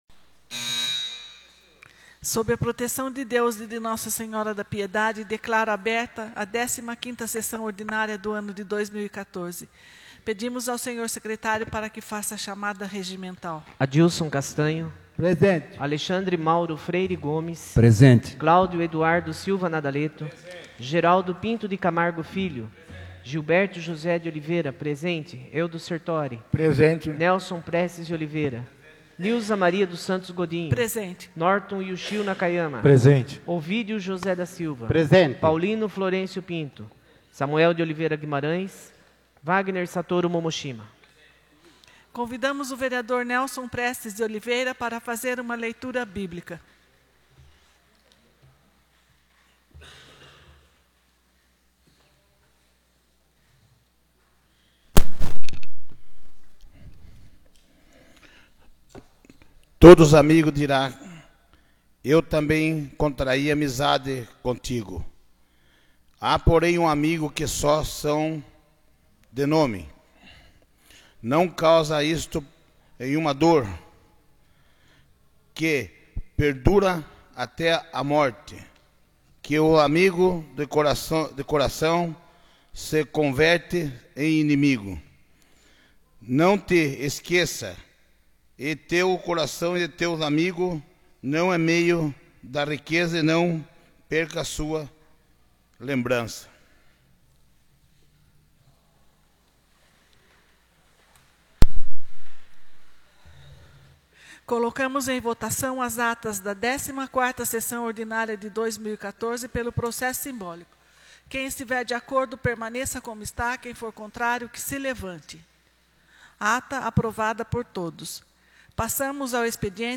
15ª Sessão Ordinária de 2014